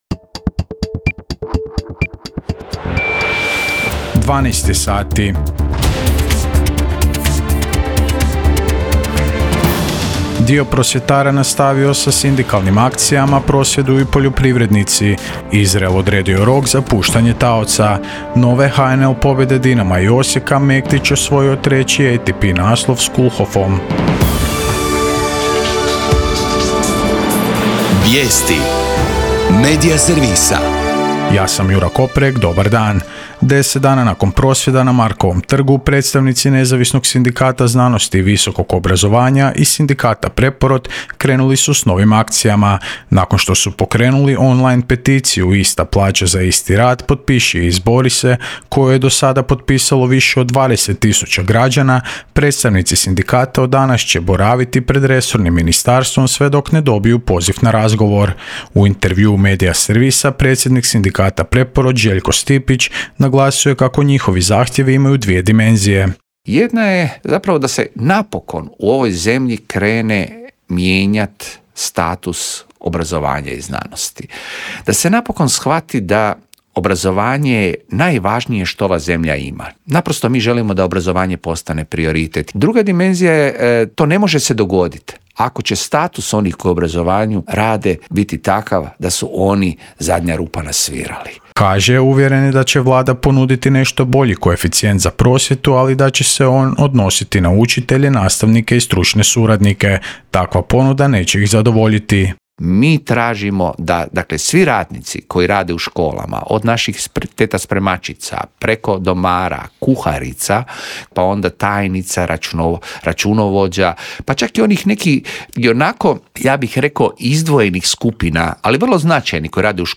VIJESTI U PODNE